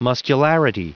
Prononciation du mot muscularity en anglais (fichier audio)
Vous êtes ici : Cours d'anglais > Outils | Audio/Vidéo > Lire un mot à haute voix > Lire le mot muscularity